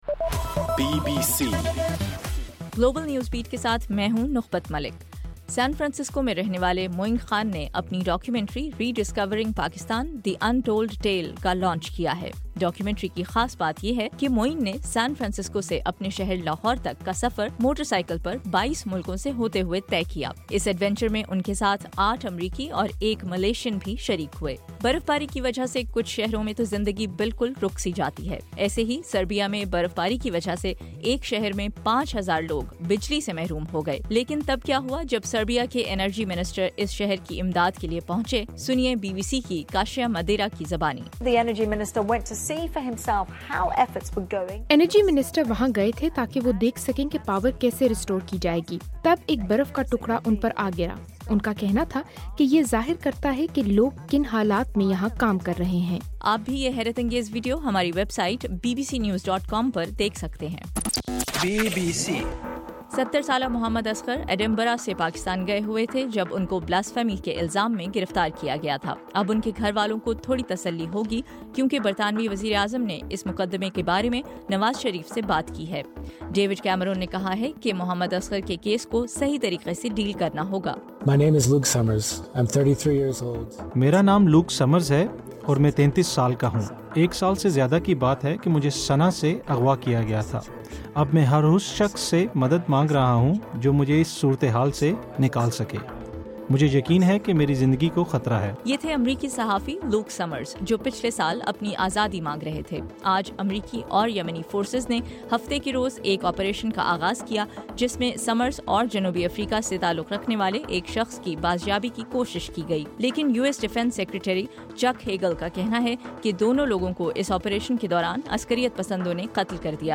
دسمبر 6: رات 8 بجے کا گلوبل نیوز بیٹ بُلیٹن